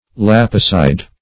What does lapicide mean?
Search Result for " lapicide" : The Collaborative International Dictionary of English v.0.48: Lapicide \Lap"i*cide\, n. [L. lapicida, fr. lapis stone + caedere to cut.]